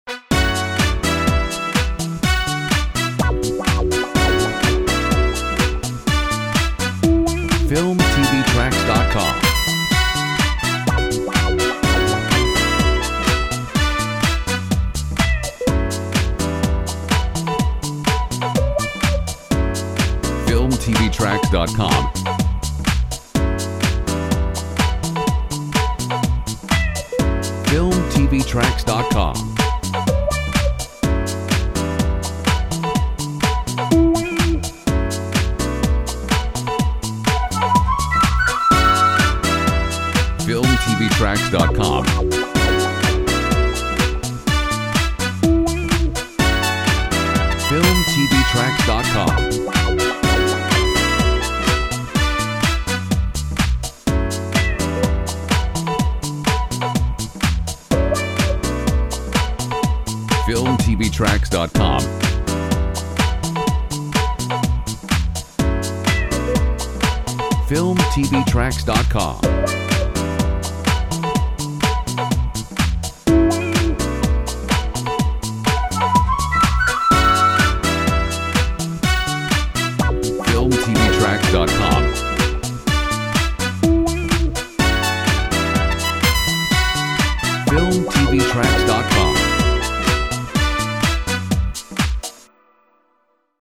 Funky Royalty free music